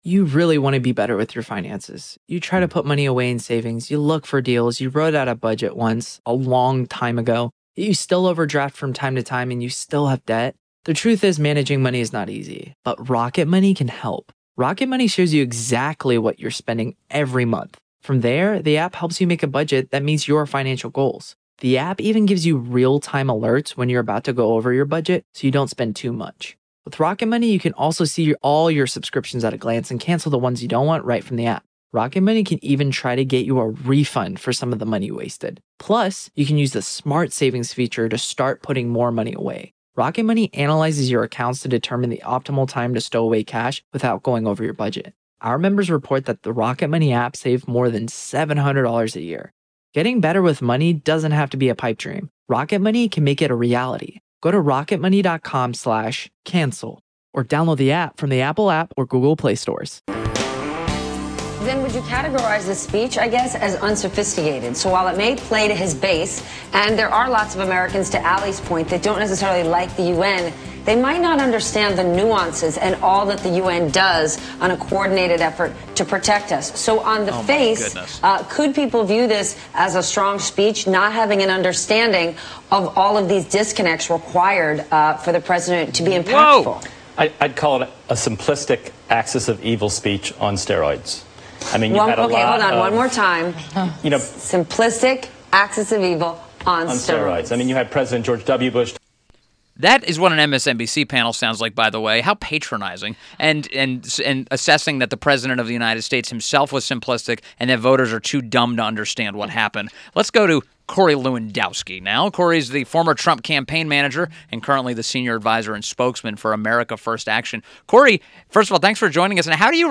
WMAL Interview - COREY LEWANDOWSKI - 09.20.17